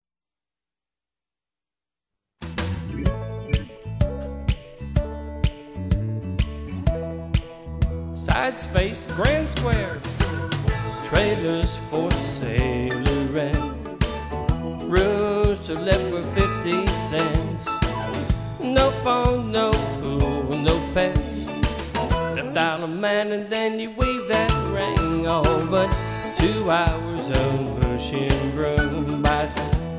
Singing Call
Voc